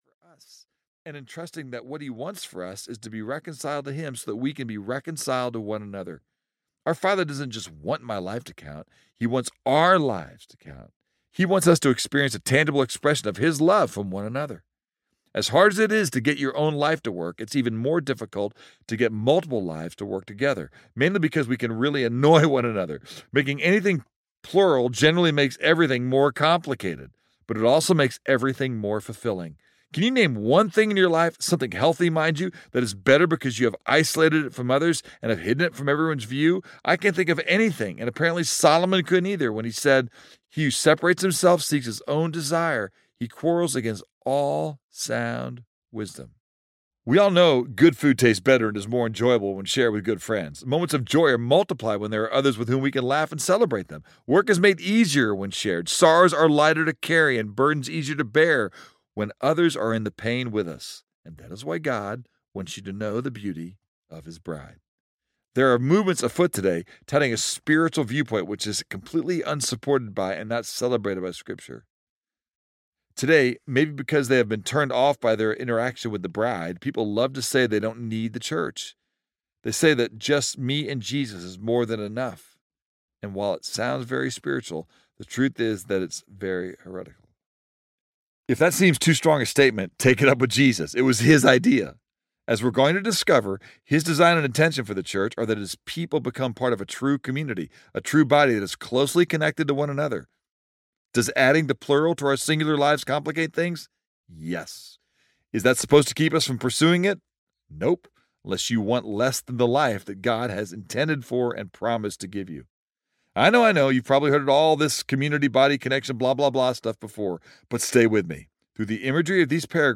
Come and See Audiobook